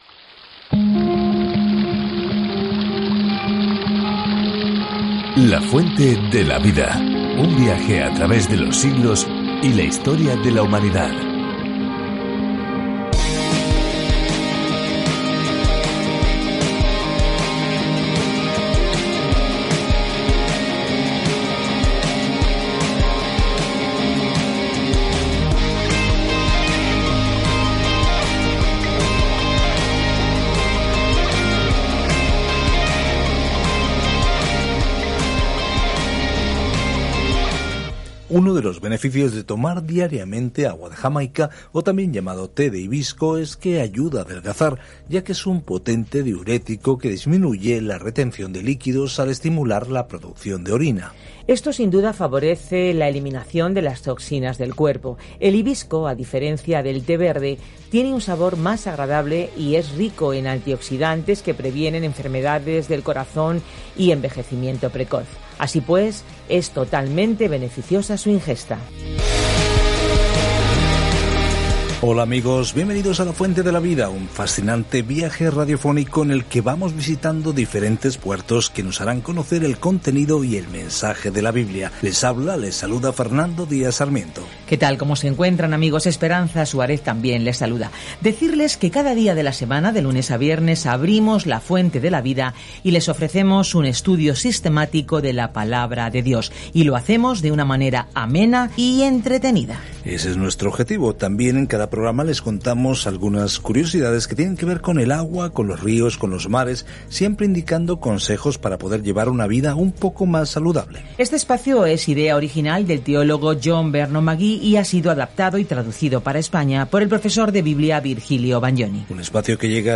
Viaje diariamente a través de Jonás mientras escucha el estudio de audio y lee versículos seleccionados de la palabra de Dios.